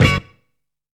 SNAKE HIT.wav